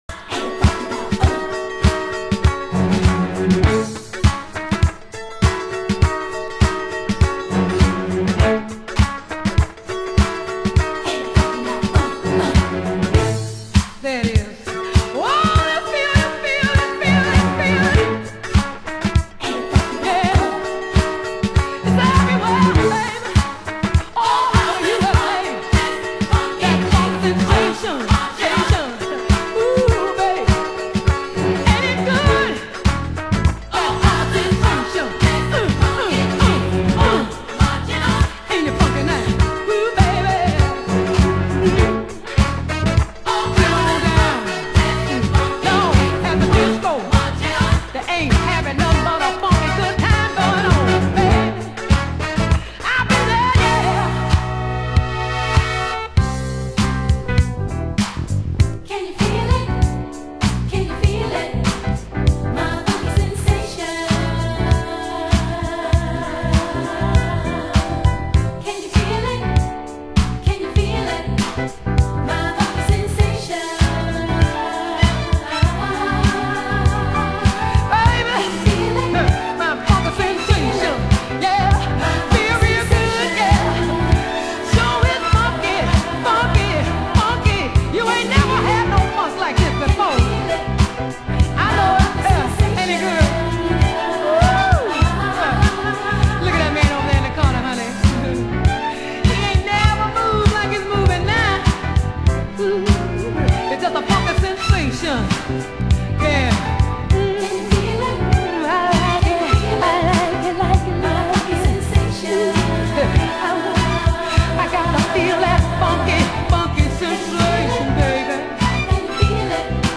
Vocal